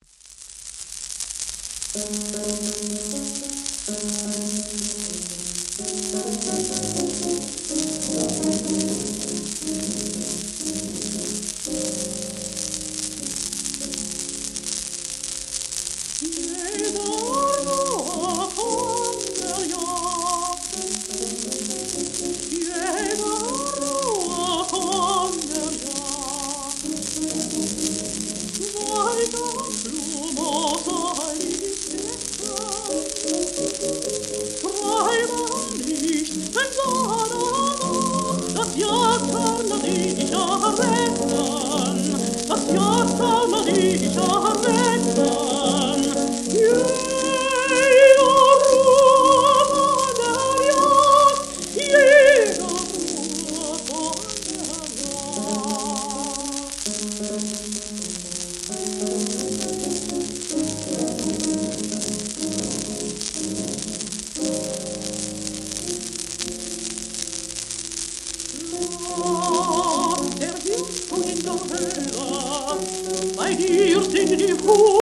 エレナ・ゲルハルト(Ms:1883-1961)
w/G.ムーア(p)
1939年に6枚組で予約販売されたプライベート録音盤のうちの一枚